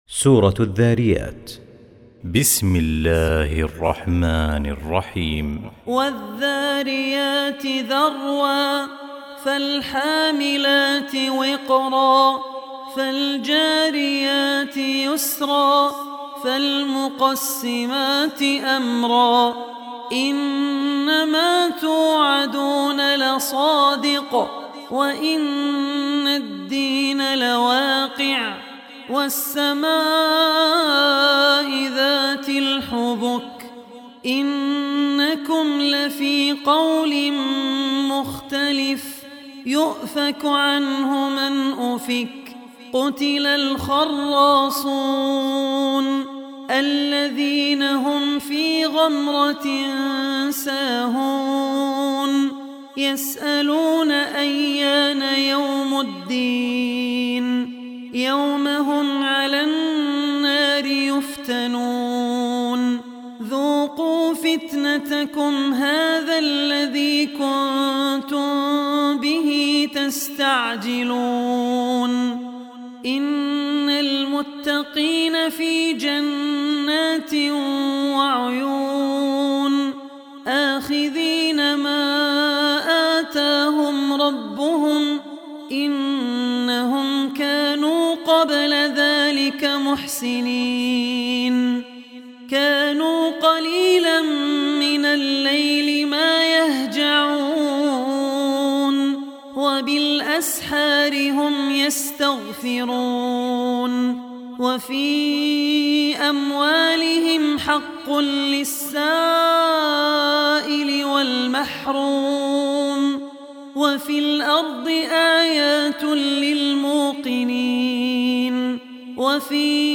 Surah Adh-Dhariyat Recitation by Al Ossi
Surah Adh-Dhariyat arabic recitation free mp3.